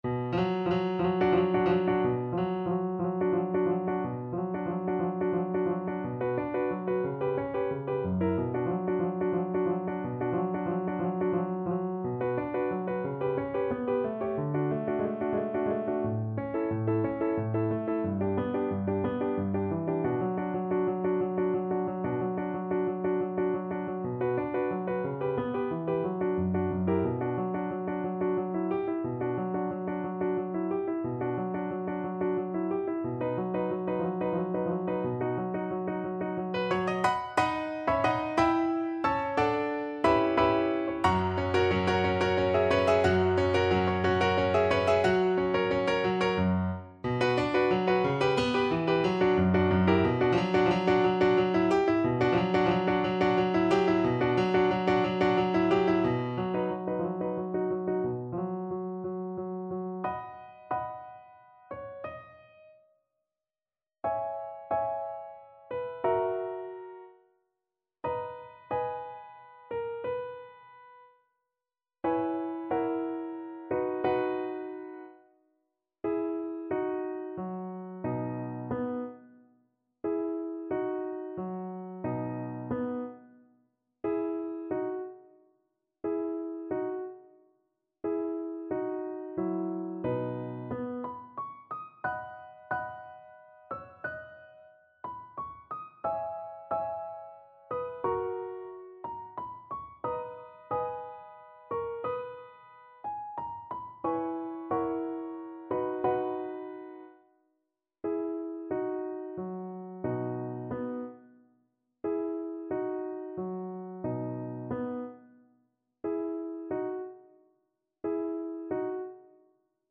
Andante, quasi allegro =90
6/8 (View more 6/8 Music)
Classical (View more Classical Viola Music)